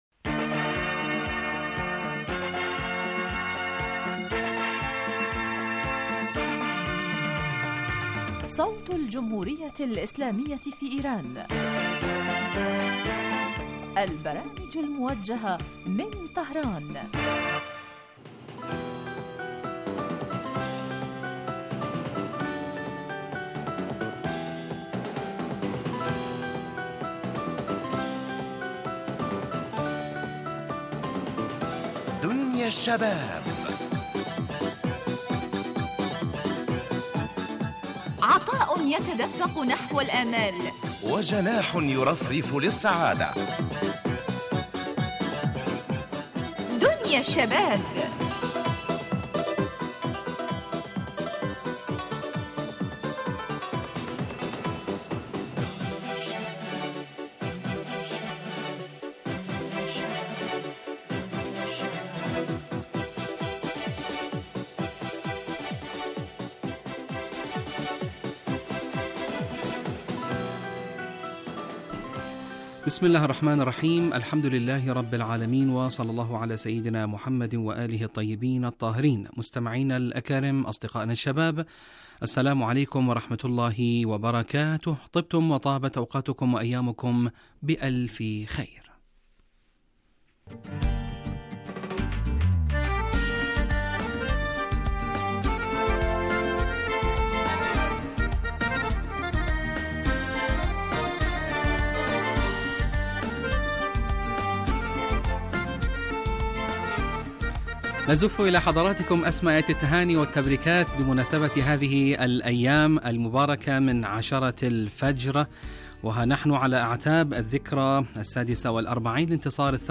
برنامج اجتماعي غني بما يستهوي الشباب من البلدان العربية من مواضيع مجدية و منوعة و خاصة ما يتعلق بقضاياهم الاجتماعية وهواجسهم بالتحليل والدراسة مباشرة علي الهواء.